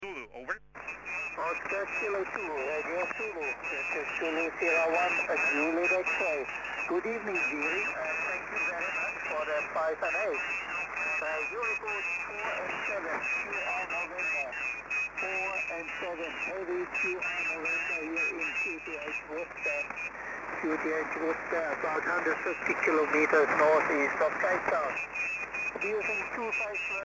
Jak posloucháte DXy v pásmu 80m?